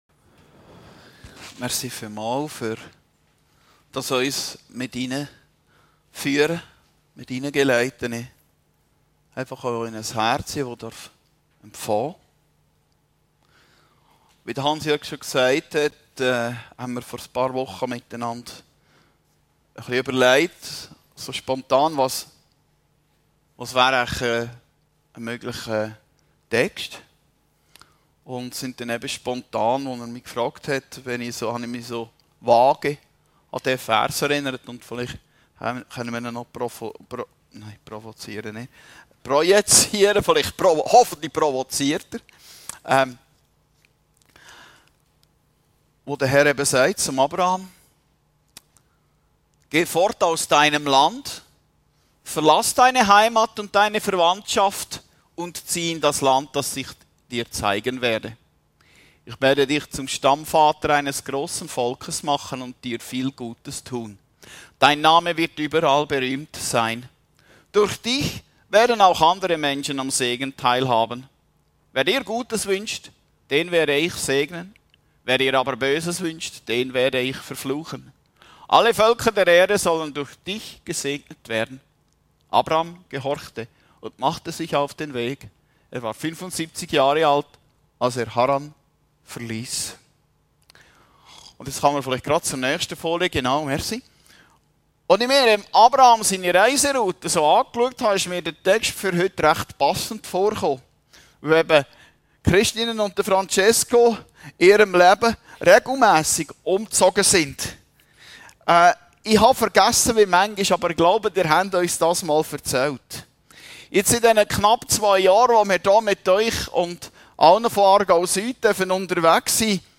Predigten Heilsarmee Aargau Süd – BERUFUNG: WIE GEHT DAS?